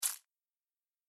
دانلود آهنگ جنگل 20 از افکت صوتی طبیعت و محیط
جلوه های صوتی
دانلود صدای جنگل 20 از ساعد نیوز با لینک مستقیم و کیفیت بالا